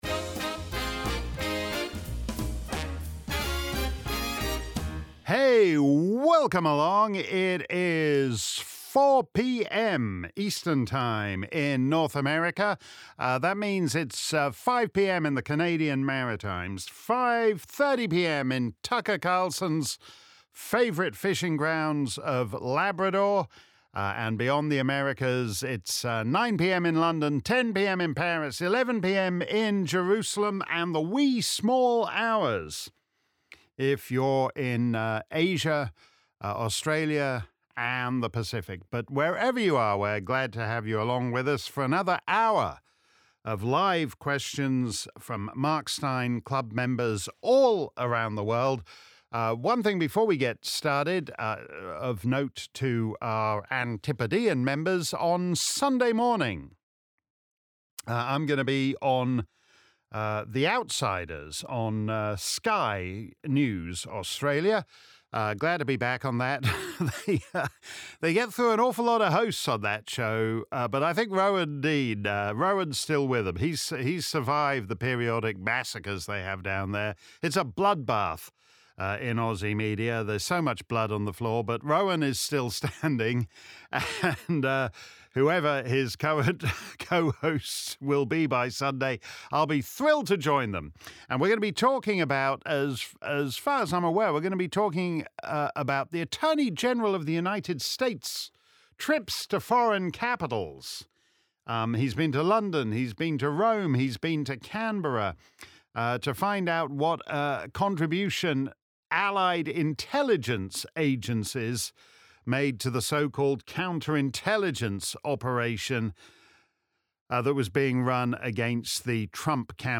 If you missed our livestream Clubland Q&A on Friday afternoon, here's the action replay. Simply click above and settle back for an hour of my answers to questions from Mark Steyn Club members around the planet.